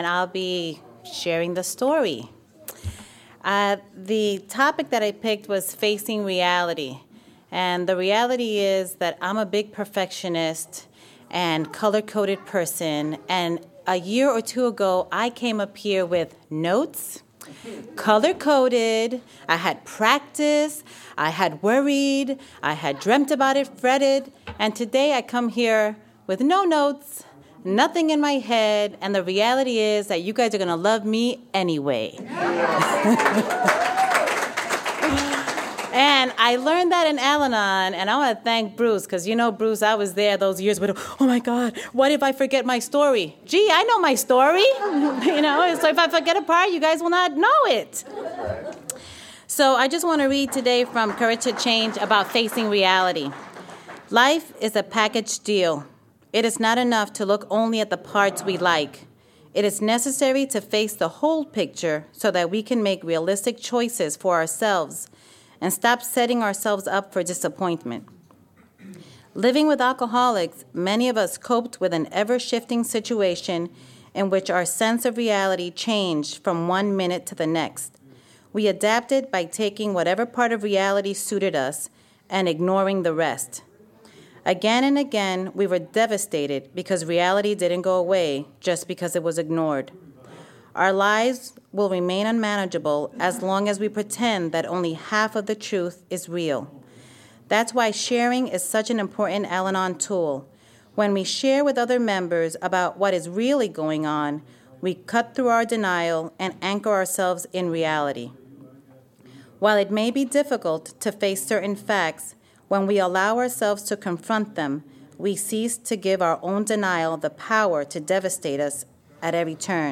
Saturday Night Al-Anon Speaker